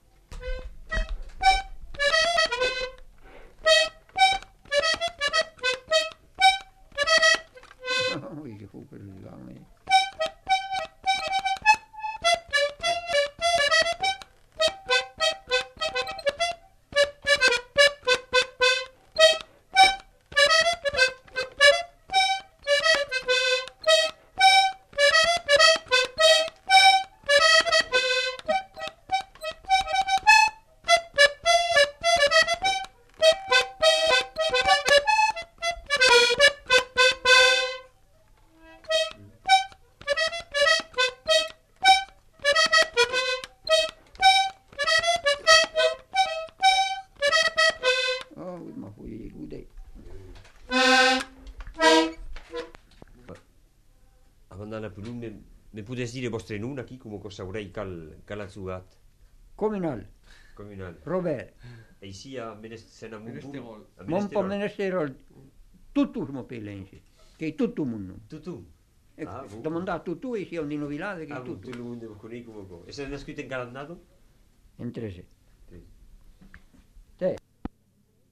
Genre : morceau instrumental
Instrument de musique : accordéon diatonique
Danse : polka piquée
Notes consultables : L'informateur se présente en fin de séquence.
Ecouter-voir : archives sonores en ligne